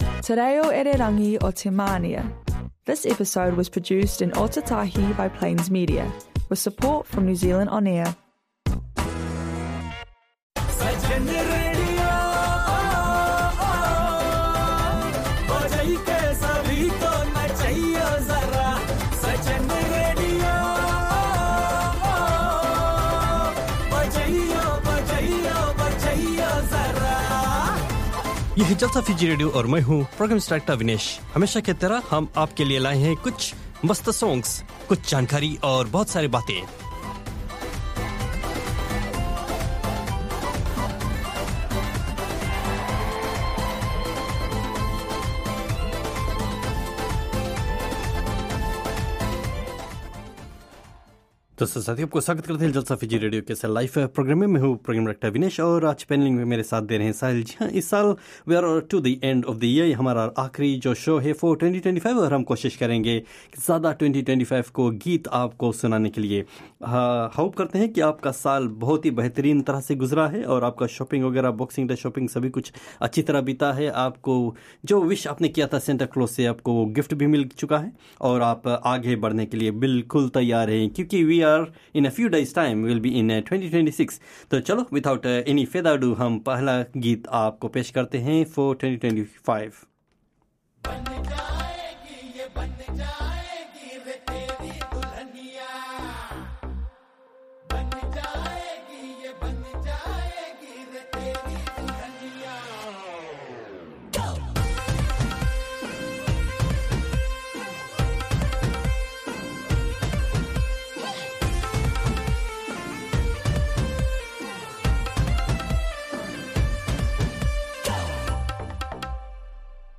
It's time to party with our Fiji Indian radio programme! Every Saturday at 5pm there's plenty of music, news of cultural events and fun to help you get in the mood to party!